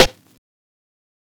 TC2 Snare 13.wav